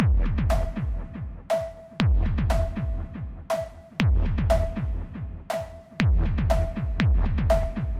Tag: 120 bpm Electro Loops Drum Loops 1.35 MB wav Key : Unknown